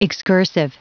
Prononciation du mot excursive en anglais (fichier audio)
Prononciation du mot : excursive